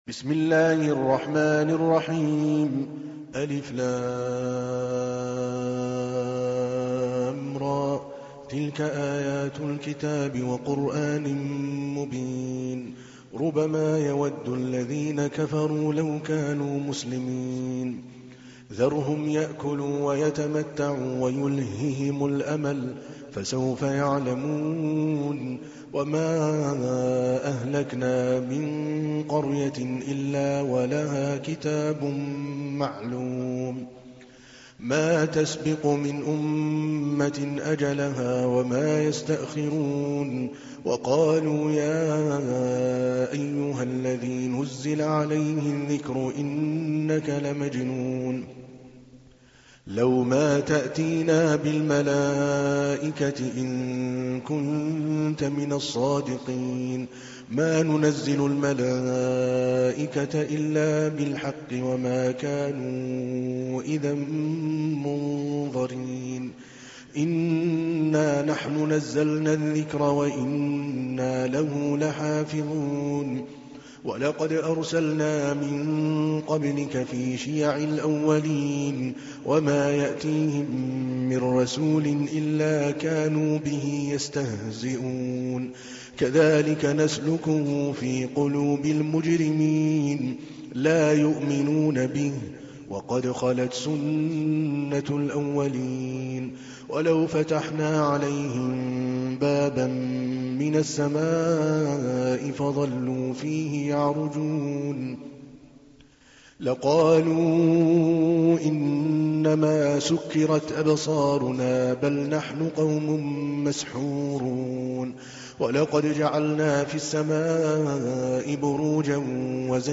تحميل : 15. سورة الحجر / القارئ عادل الكلباني / القرآن الكريم / موقع يا حسين